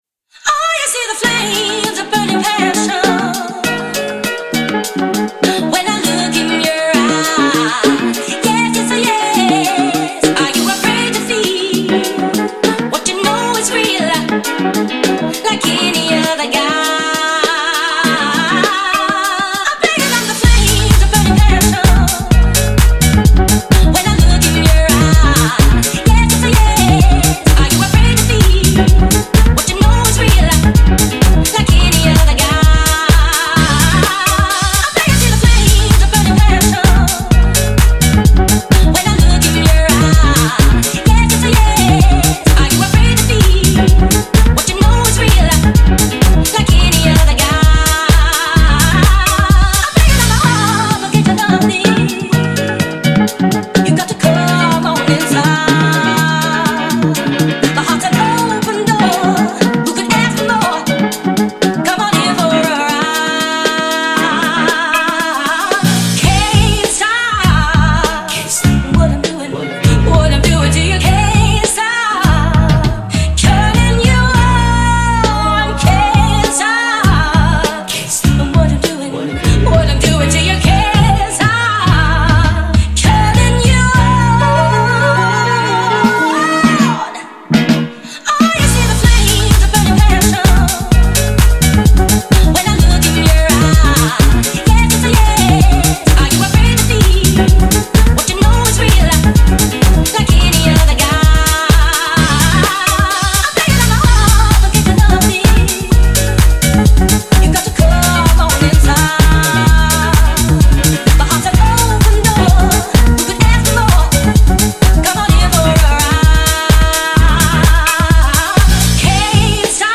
Audio QualityPerfect (High Quality)
100-128 BPM.